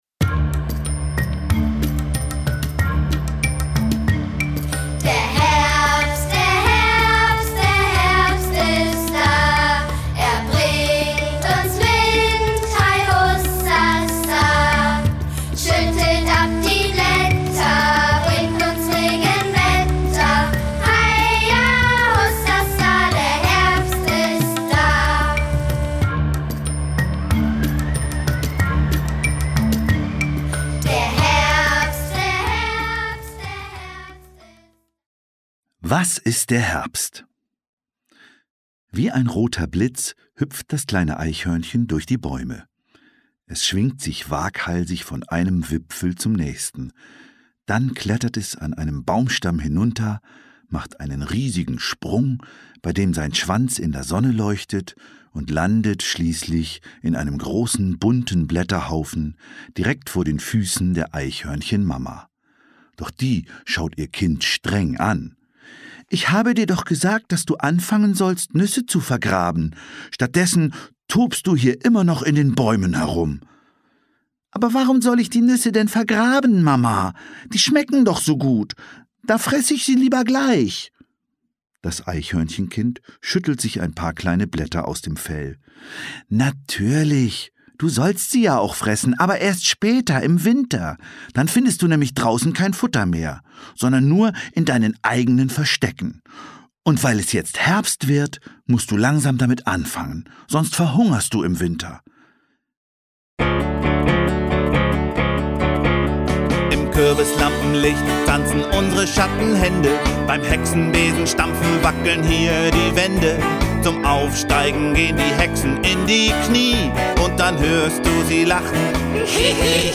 Lieder, Geschichten und Gedichte zur Herbstzeit